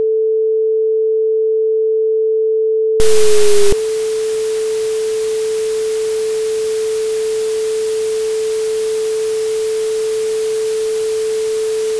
A tear in the pitch. A friction in the logic gates.
This noise—this “Barkhausen crackle” of magnetic domains snapping into place—is not inefficiency.